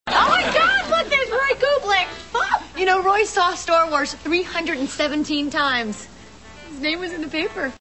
These are .mp3 soundbites from the NBC television show "Friends."